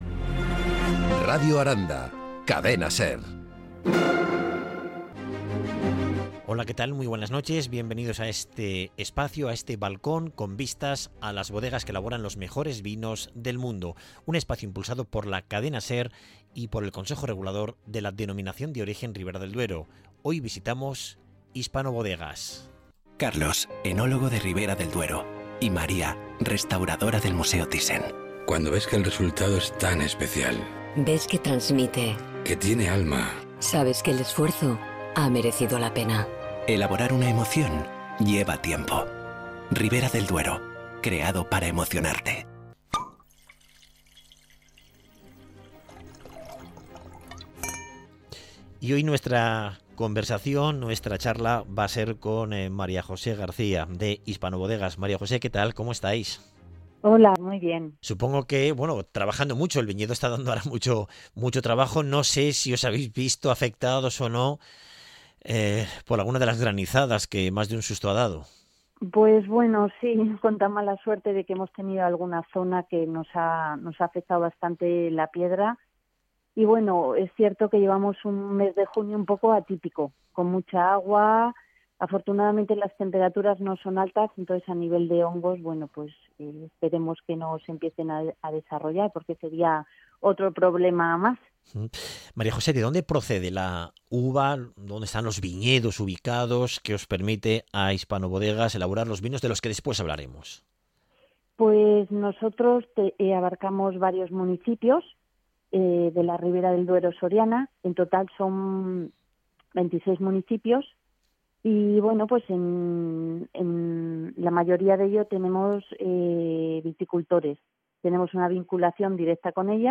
Cadena Ser – entrevista